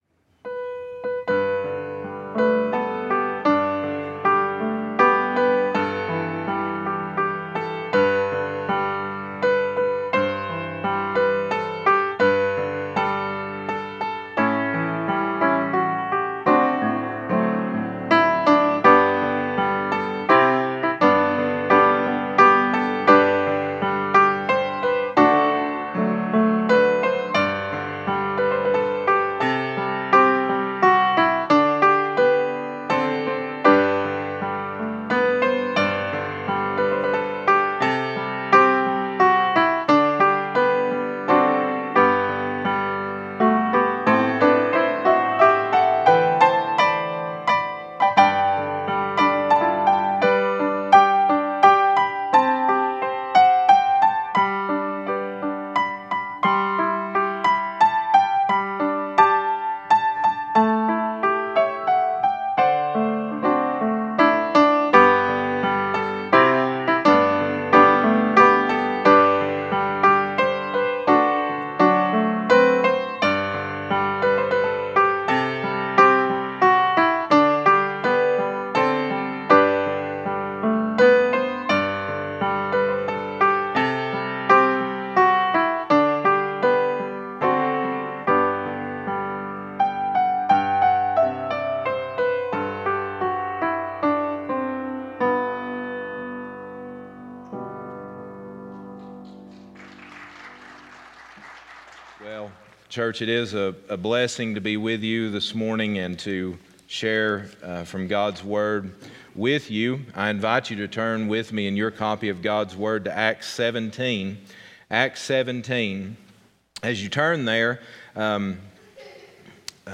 Passage: Acts 17:16-34 Service Type: Sunday Morning « Happy Homecoming!